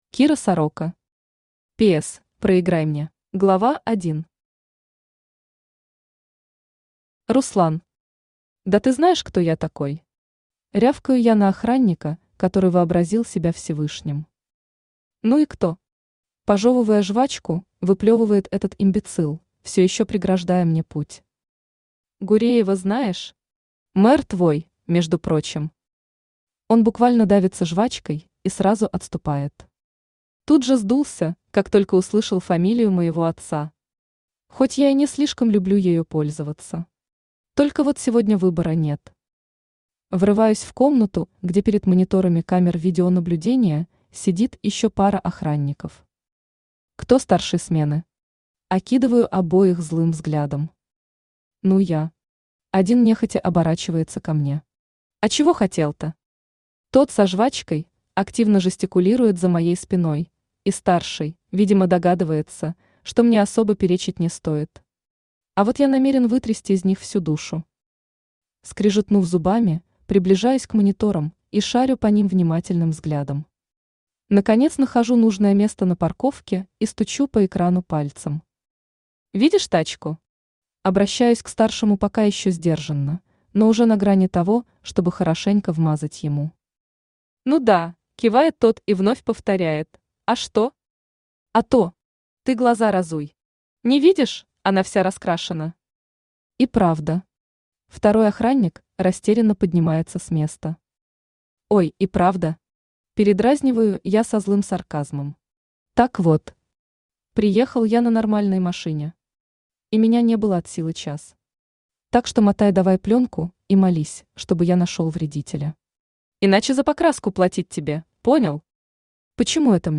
Аудиокнига PS: Проиграй мне | Библиотека аудиокниг
Aудиокнига PS: Проиграй мне Автор Кира Сорока Читает аудиокнигу Авточтец ЛитРес.